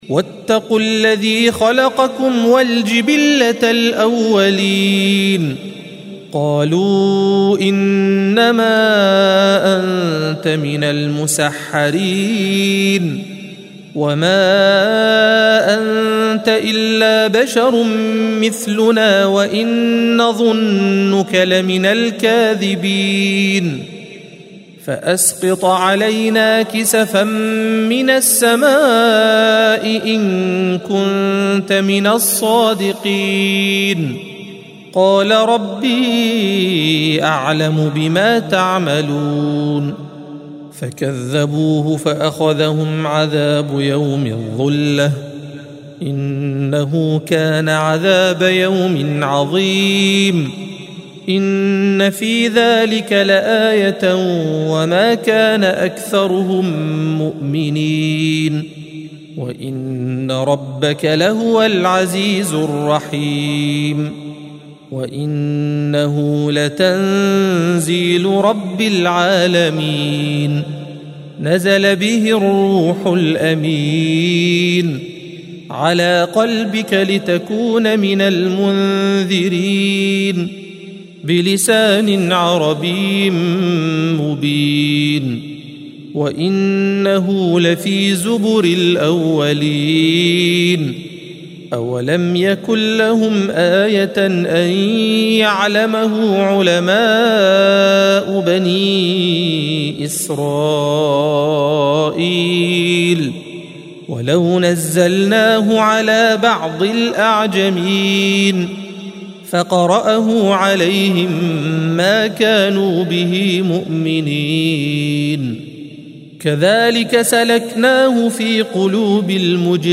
الصفحة 375 - القارئ